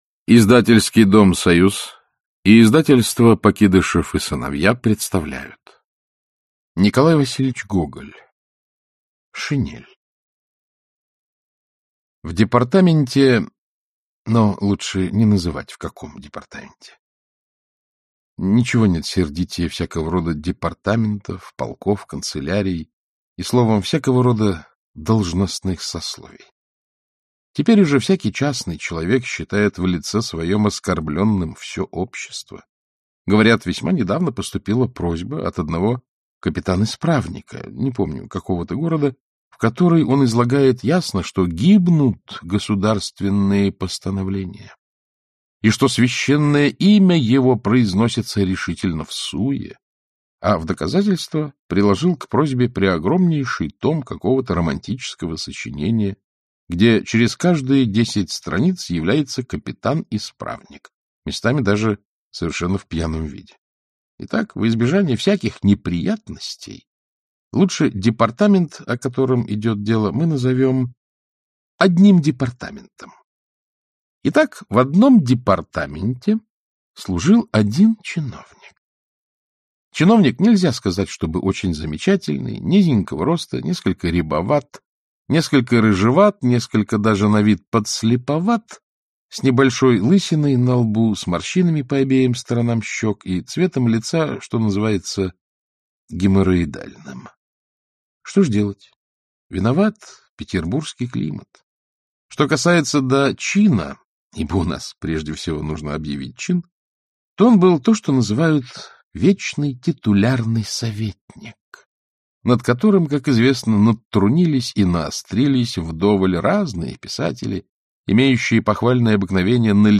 Aудиокнига Шинель Автор Николай Гоголь Читает аудиокнигу Александр Клюквин.